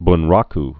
(bn-räk, bn-)